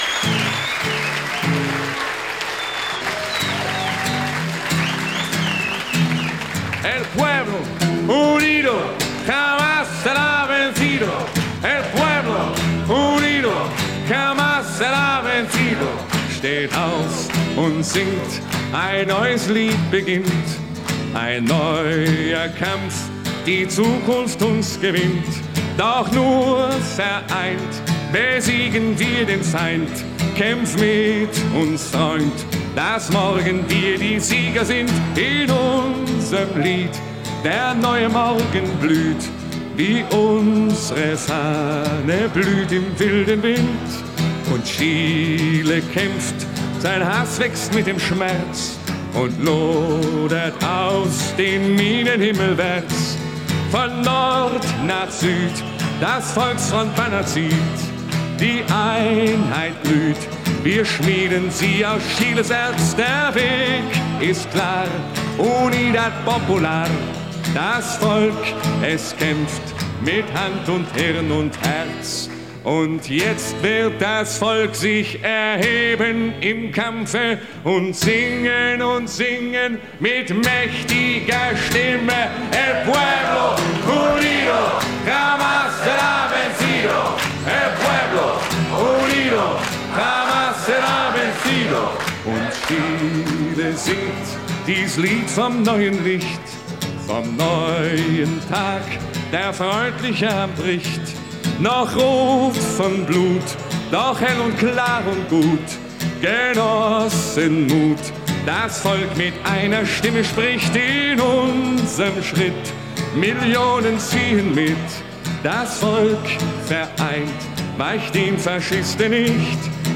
Описание: Немецкая версия чилийской революционной песни